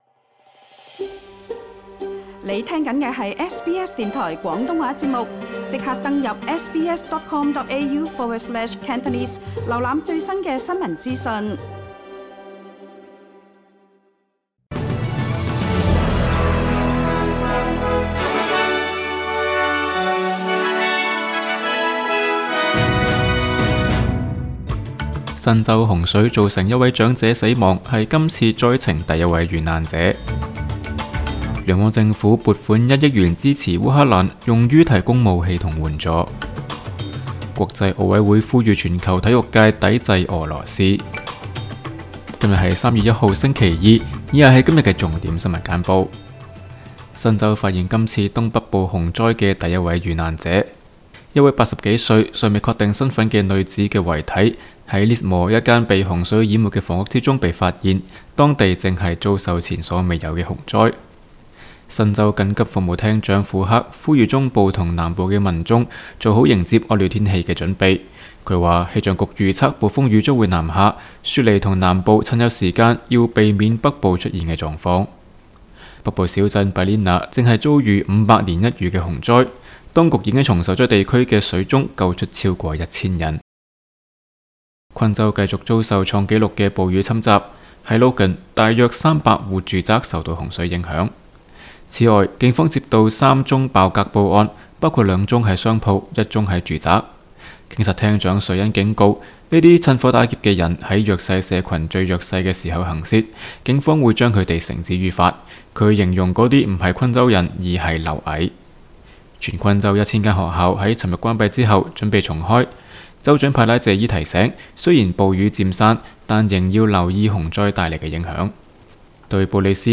SBS 新闻简报（3月1日）
SBS 廣東話節目新聞簡報 Source: SBS Cantonese